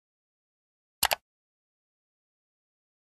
mouse-click-sound-effect-hd.mp3